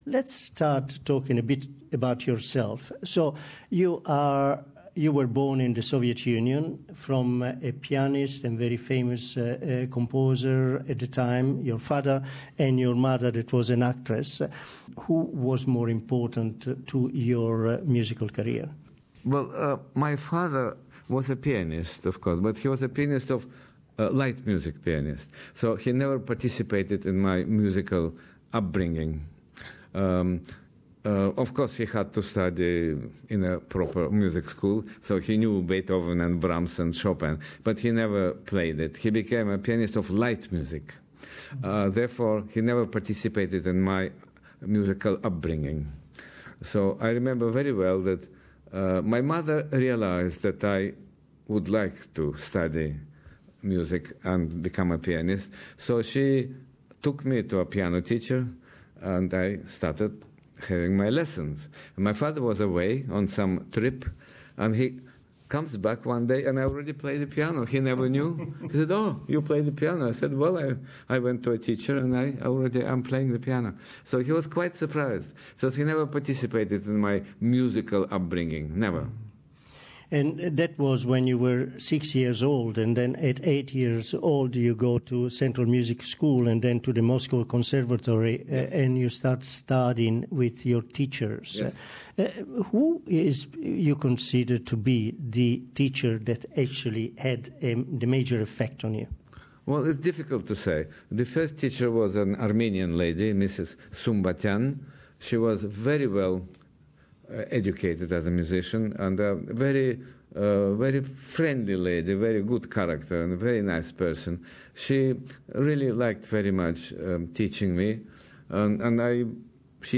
Vladimir Ashkenazy, our interview